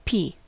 Ääntäminen
IPA : /ˈpiː/